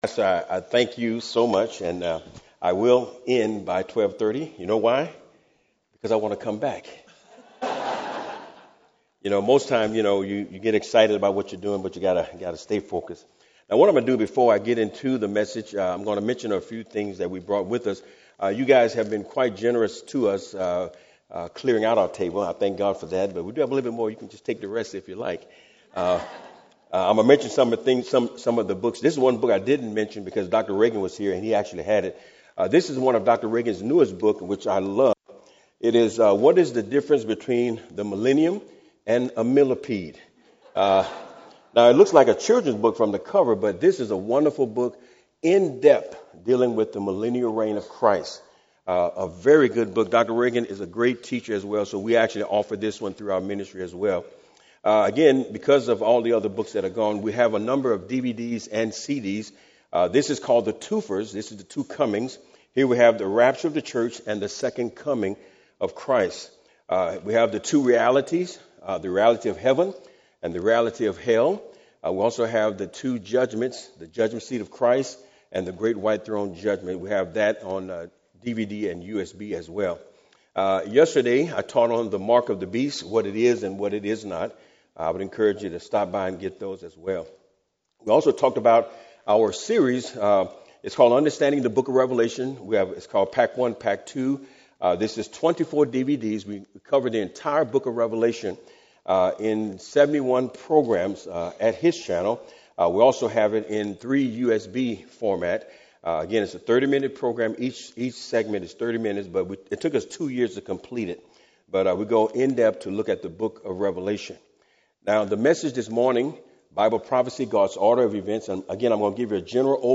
Sermons
2023 Prophecy Conference